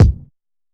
Havoc Kick 14.wav